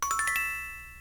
message-received.mp3